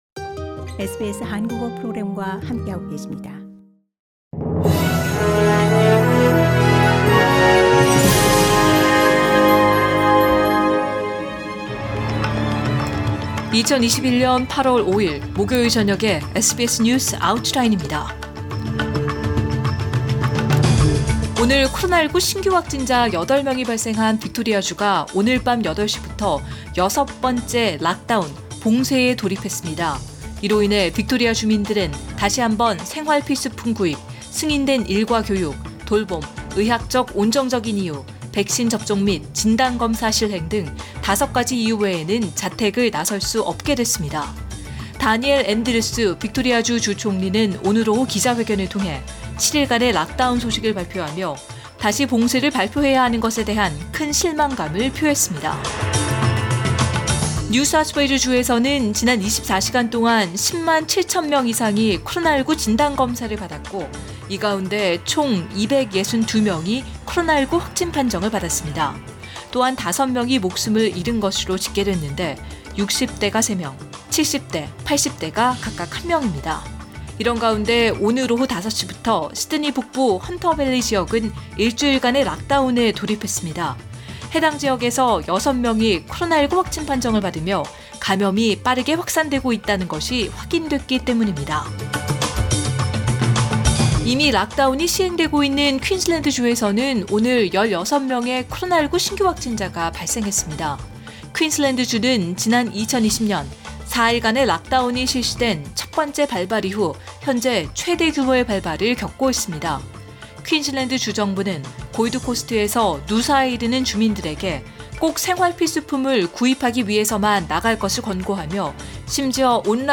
2021년 8월 5일 목요일 저녁의 SBS 뉴스 아우트라인입니다.